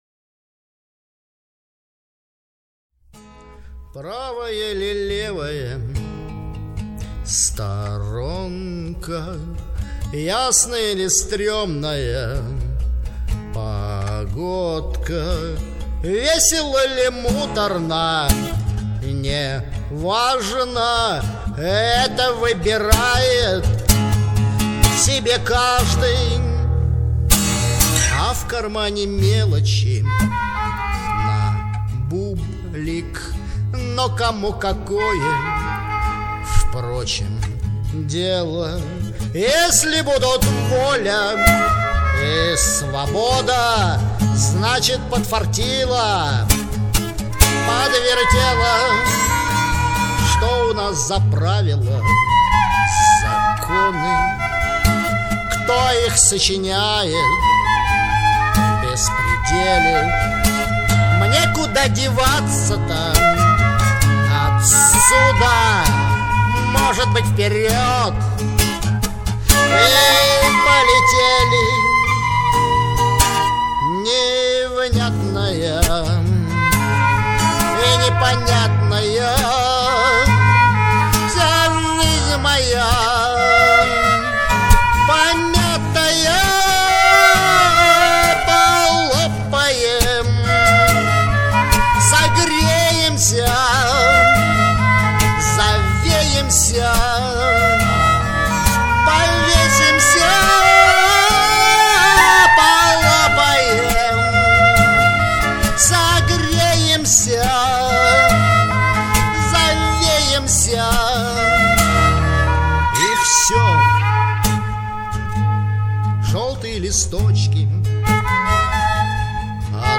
Романтическая музыка Лирическая музыка Авторские песни